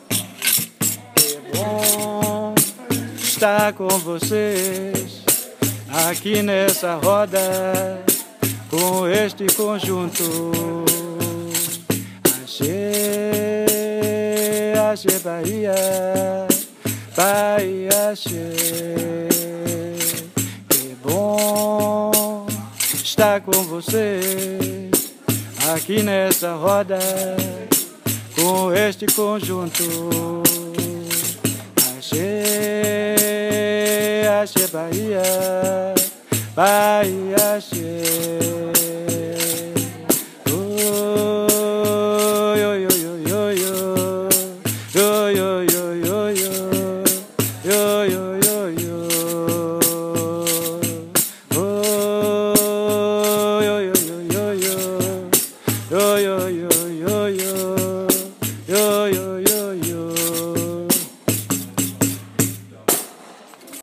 har optaget sangen efter vores træning i mandags
sammen med pandeiro rytmen